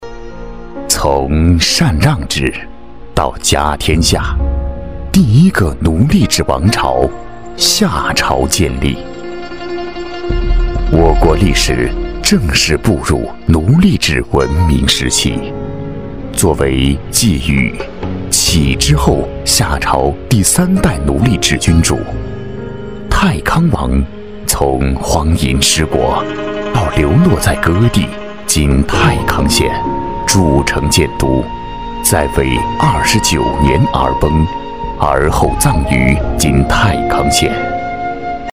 纪录片男135号（探访夏王
娓娓道来 文化历史
大气浑厚，磁性稳重男音，擅长城市旅游宣传、记录片讲述等题材。